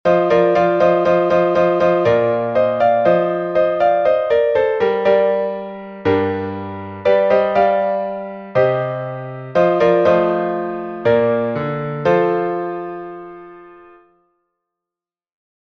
Глас 5